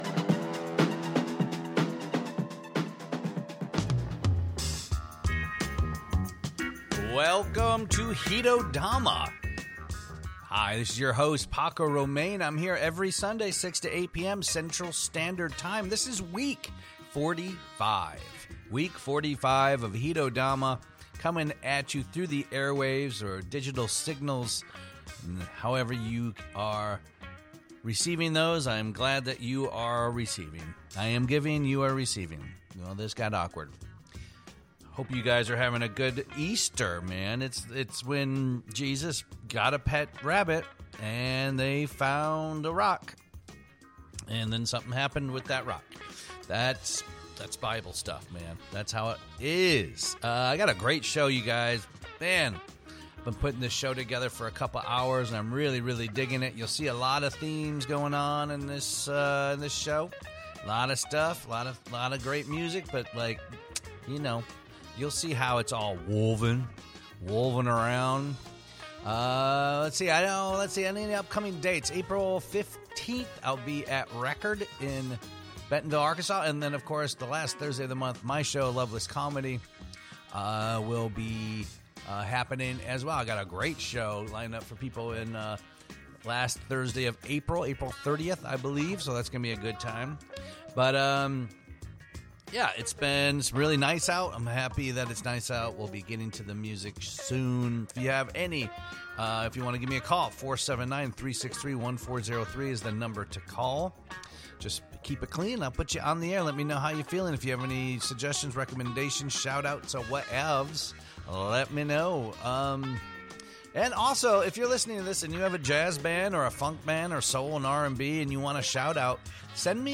Hito Dama features jazz, funk, soul, comedy, call-ins, and more!
Genres blur, borders dissolve, and what remains is groove, mood, and intention.
The show also has live call-ins from some very colorful characters, comedy snippets, and fake ads!